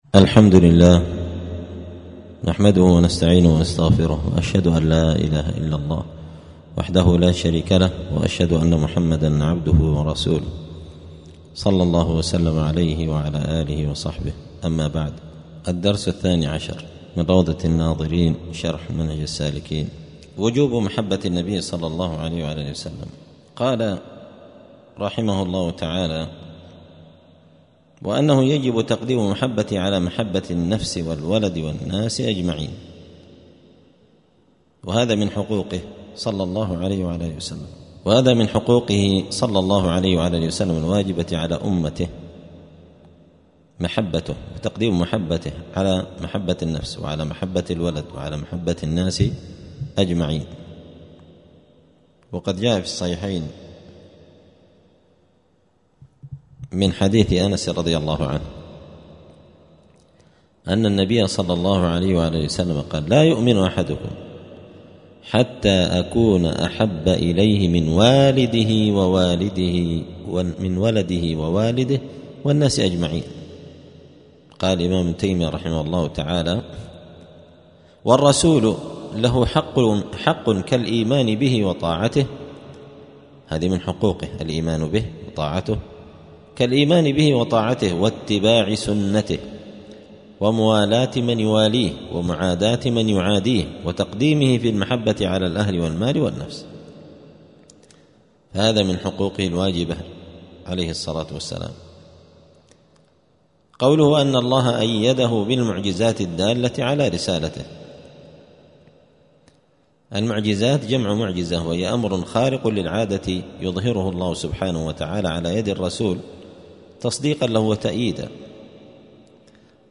دار الحديث السلفية بمسجد الفرقان قشن المهرة اليمن 📌الدروس اليومية